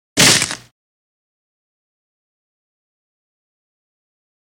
Small Crunch | Sneak On The Lot
Wood, Small Piece, Crunched.